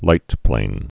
(lītplān)